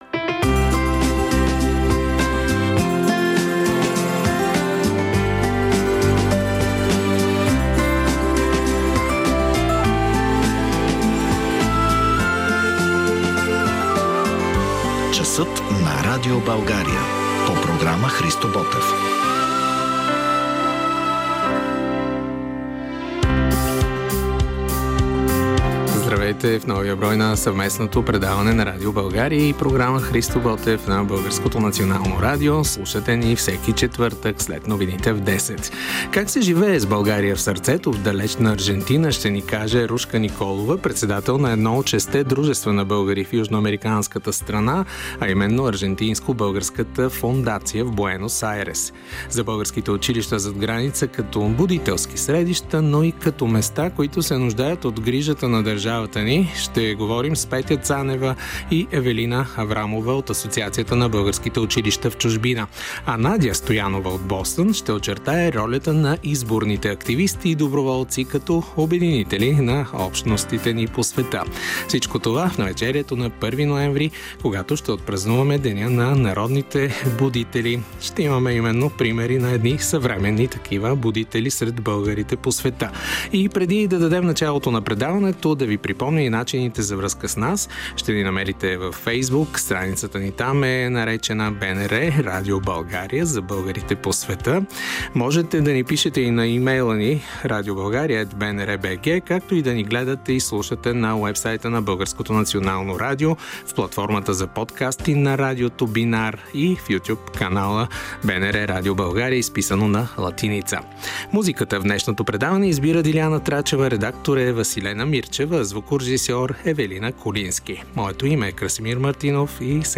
Интервю